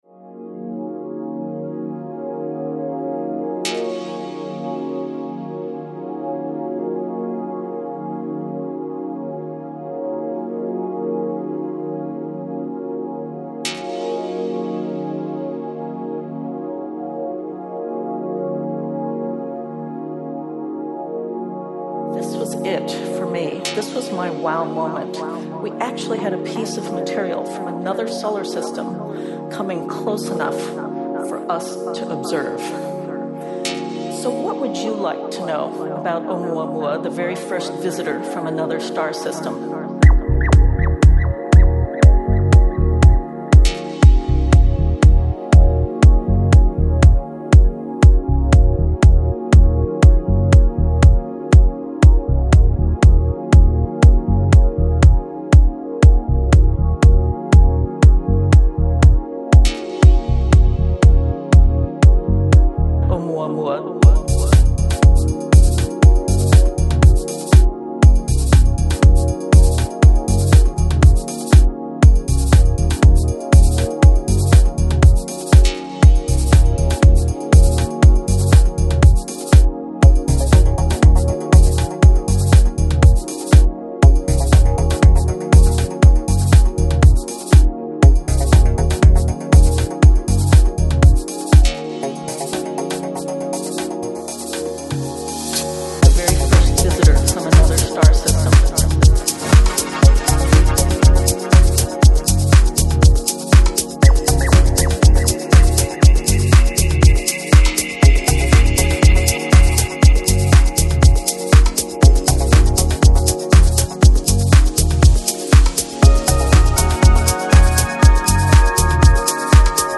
音乐流派: Soulful House, Deep House, Chill House